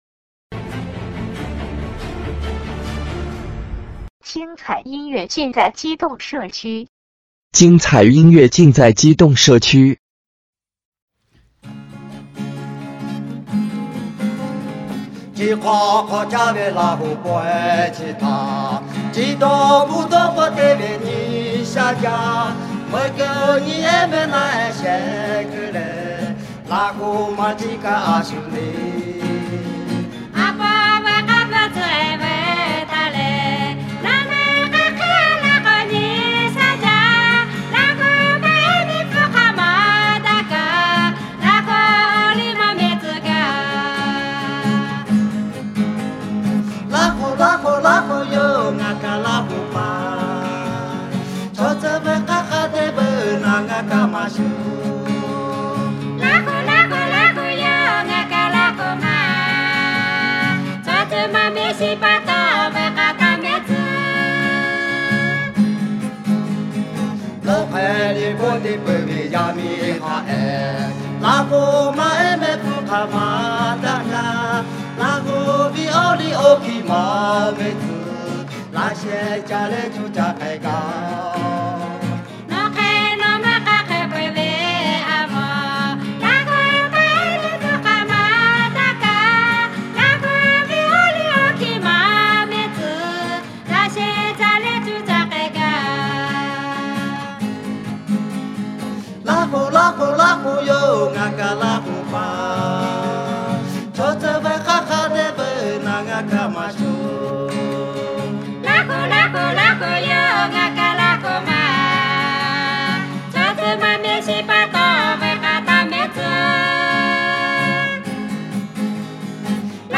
2010年11月录音